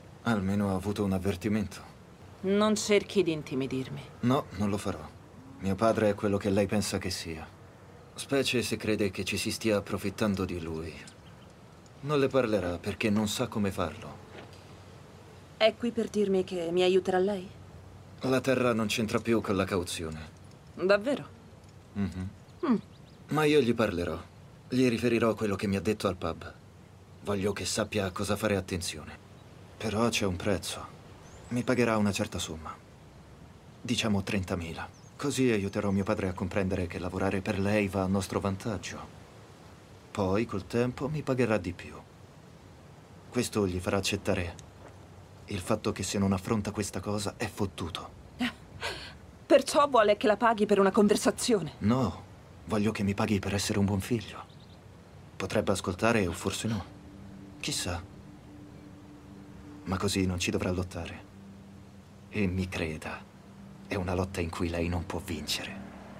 nel telefilm "Outer Range", in cui doppia Lewis Pullman.